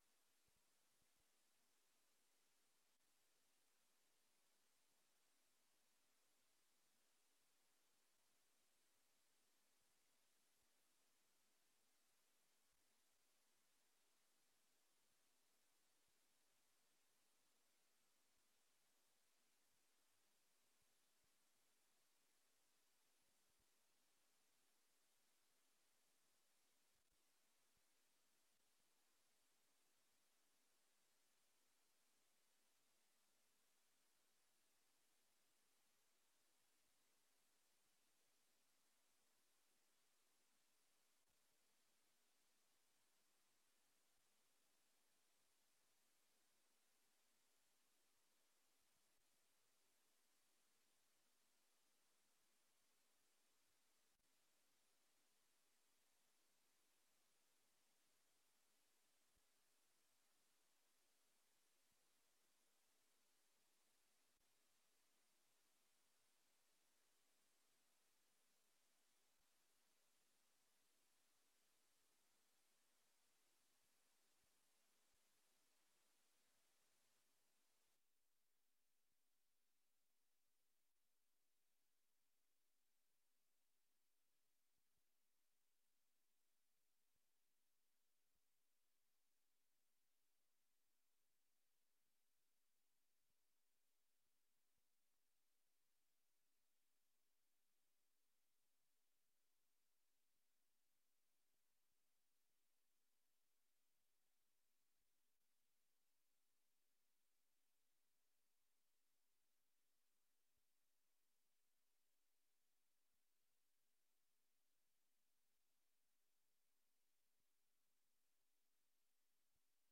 Beeldvormende vergadering 04 april 2024 19:30:00, Gemeente Dronten